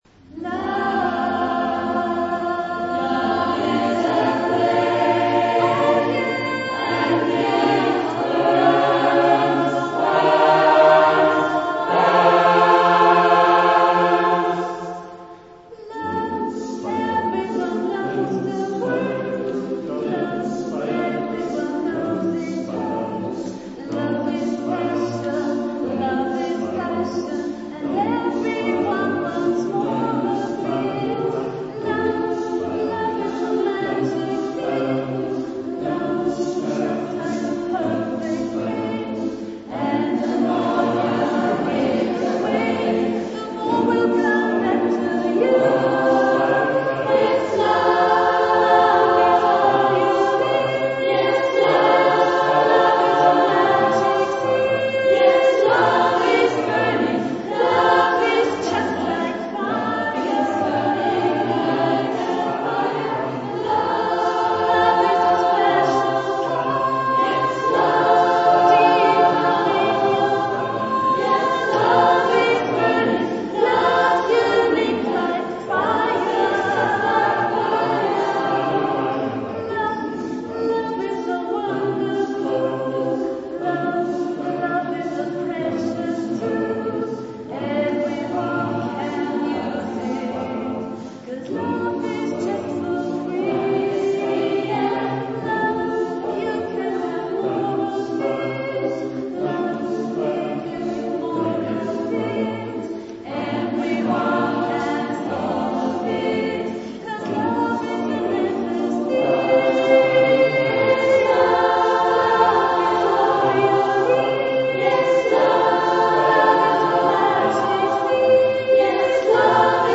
Wie man auch an einem warmen Sommerabend die Kirche voll bekommt, bewies eindrucksvoll der Chor unserer Pfarre.
Alle Solisten/innen haben mit ihren Stimmen überzeugt, der Chor bewies sein Können in den harmonisch leisen Klängen genauso wie in den rhythmischen und temperamentvollen Liedern.